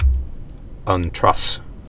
(ŭn-trŭs)